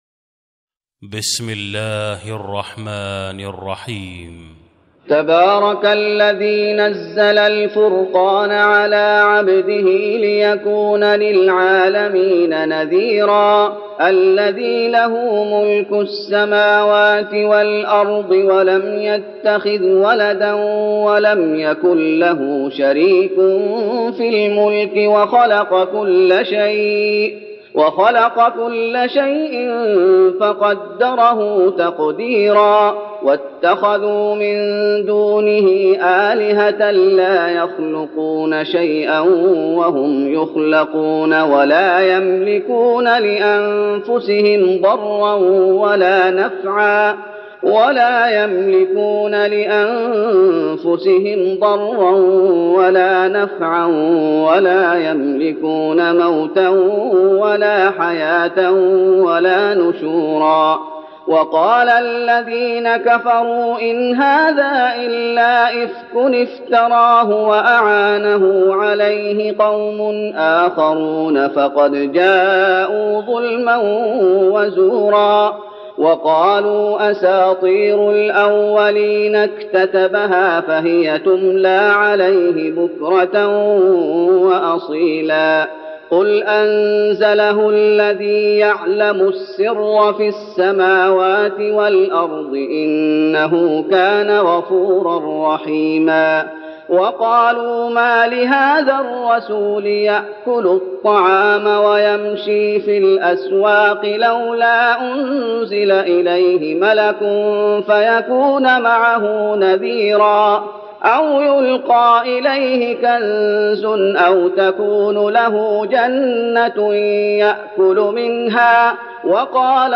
تراويح رمضان 1412هـ من سورة الفرقان (1-20) Taraweeh Ramadan 1412H from Surah Al-Furqaan > تراويح الشيخ محمد أيوب بالنبوي 1412 🕌 > التراويح - تلاوات الحرمين